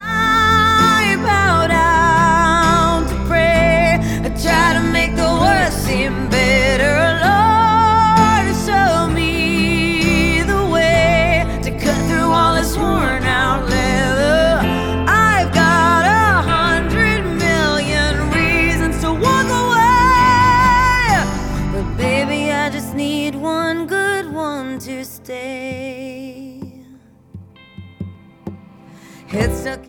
• Pop
The track is a pop song with country influences.